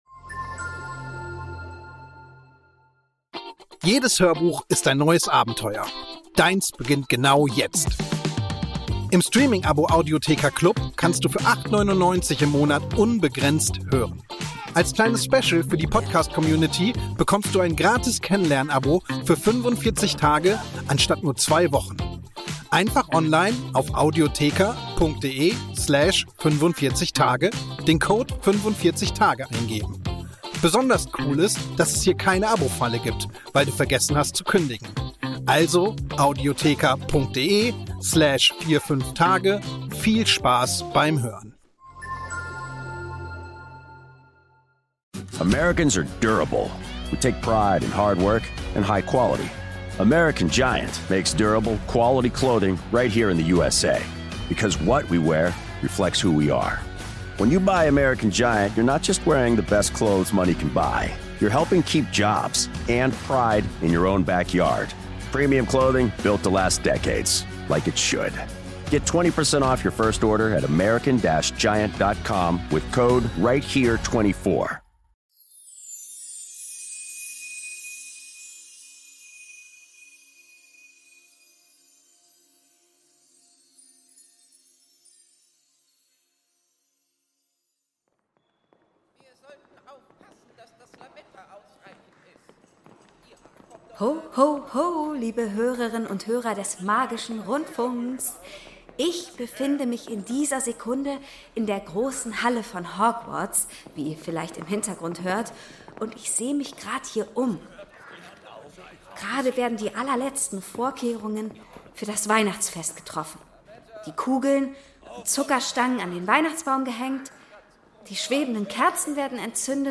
24. Türchen | Der Stern von Hogwarts - Eberkopf Adventskalender ~ Geschichten aus dem Eberkopf - Ein Harry Potter Hörspiel-Podcast Podcast